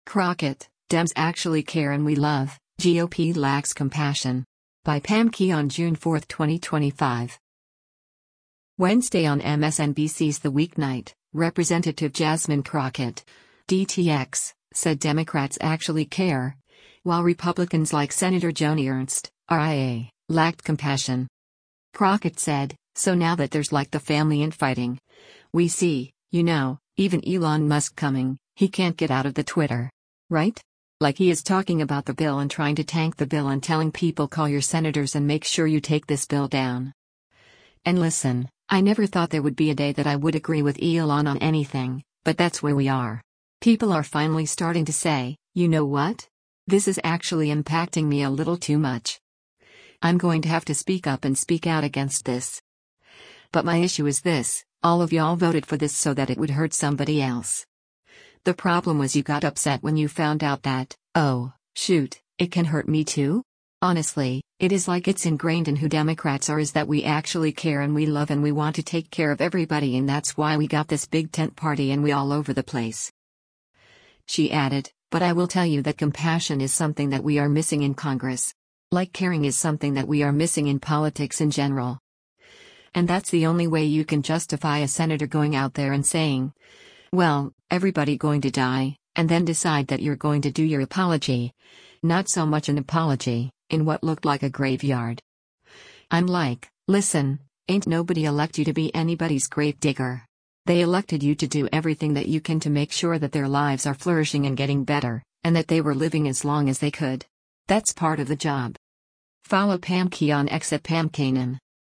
Wednesday on MSNBC’s “The Weeknight,” Rep. Jasmine Crockett (D-TX) said Democrats “actually care,” while Republicans like Sen. Joni Ernst (R-IA) lacked compassion.